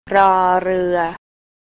รอ-เรือ
ror roer